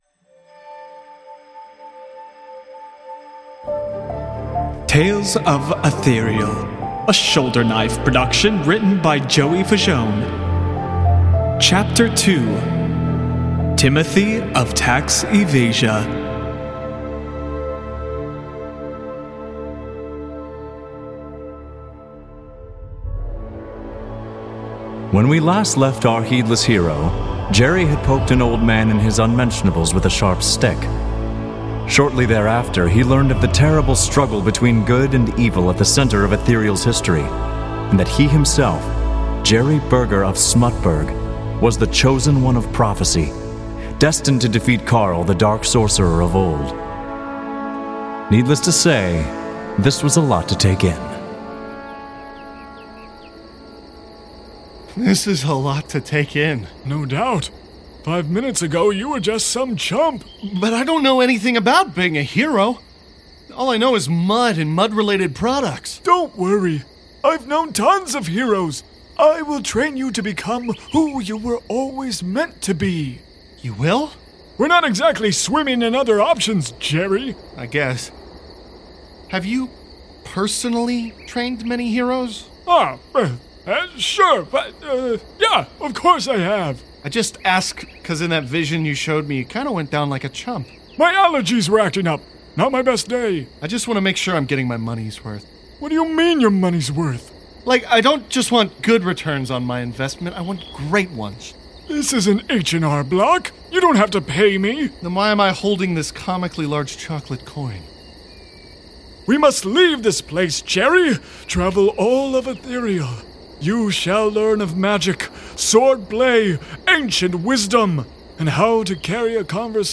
Additional SFX licensed from Pixabay